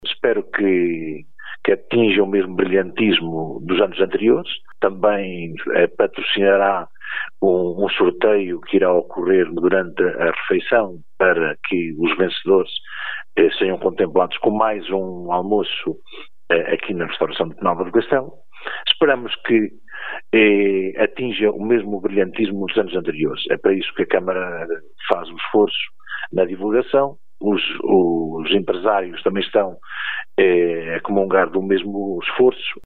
O autarca de Penalva do Castelo, diz que o cabrito do concelho é de excelente qualidade e confecionado tradicionalmente.